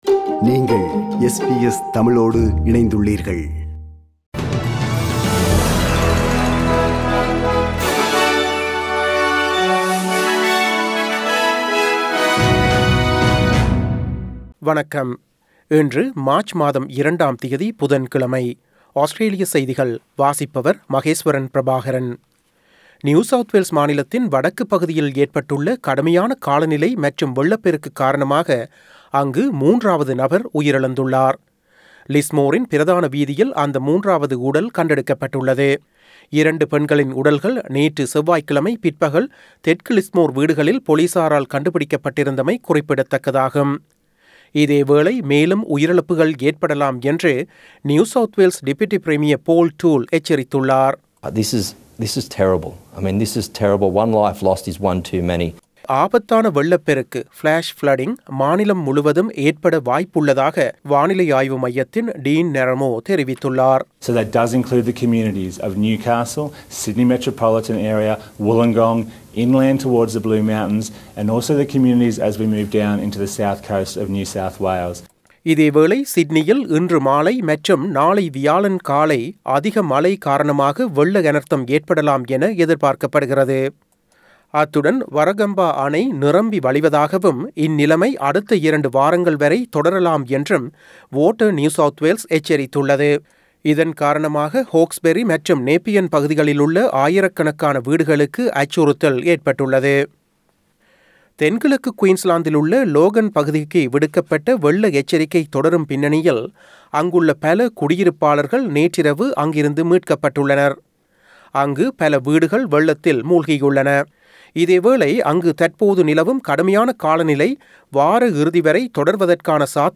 Australian news bulletin for Wednesday 02 March 2022.